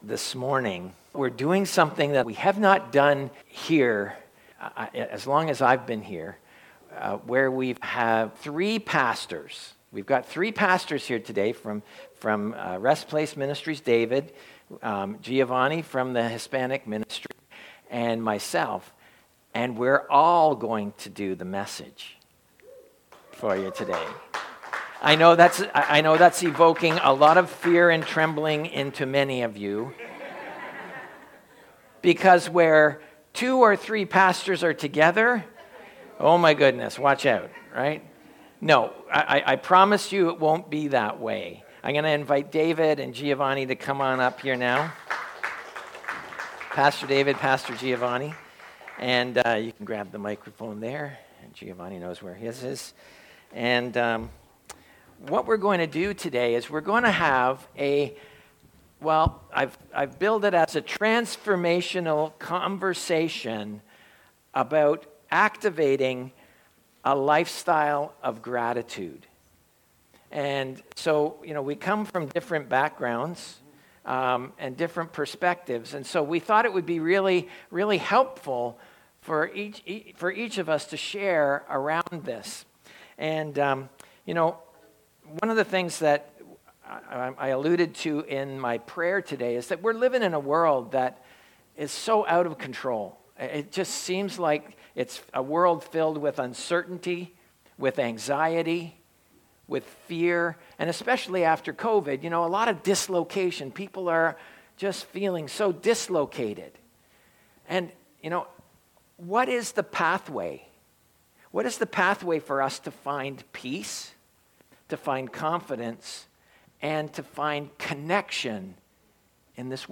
A Transformational Conversation